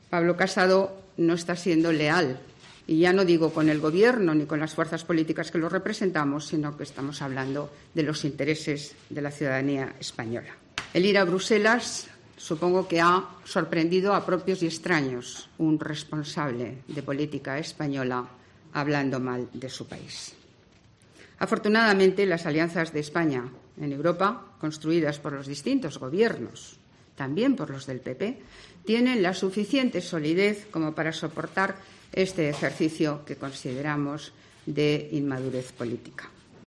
En la rueda de prensa posterior al Consejo de Ministros, Celaá ha criticado la "oposición de una derecha creciente de las fuerzas conservadoras" que buscan el veto en el Senado y el bloqueo de las cuentas en el Congreso, y que "no están representando a la mayoría" de las fuerzas políticas en la Cámara Baja.